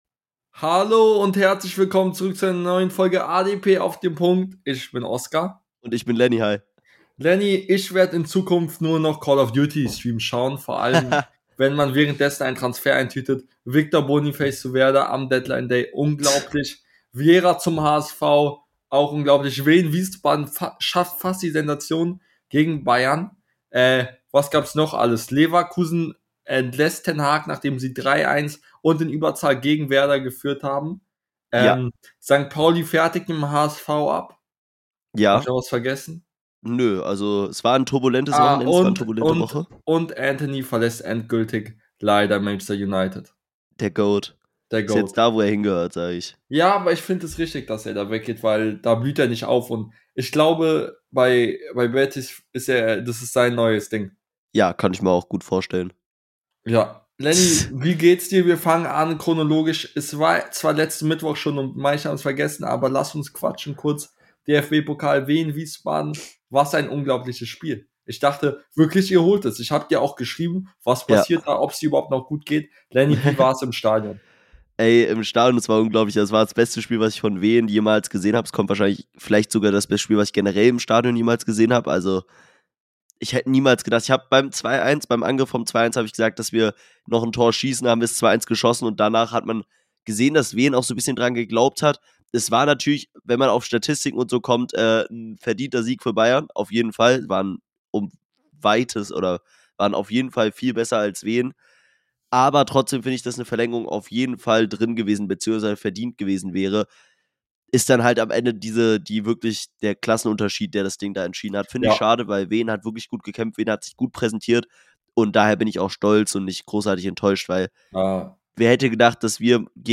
In der heutigen Folge sprechen die beiden Hosts über den Deadline Day Wahnsinn , Bonifaces Call of Duty Streams , Wehens Pokalspiel gegen Bayern und vieles mehr